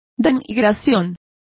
Complete with pronunciation of the translation of defamations.